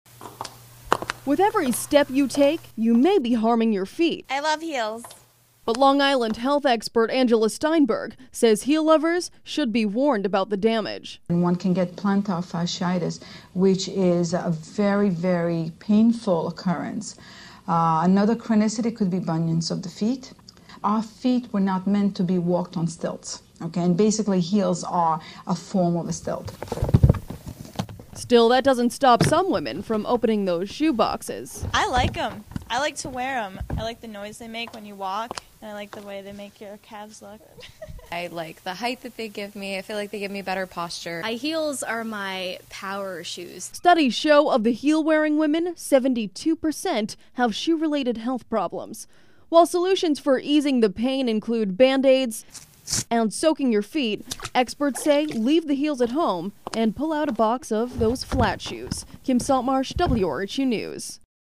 Third Place Radio I – Features - Hearst Journalism Awards Program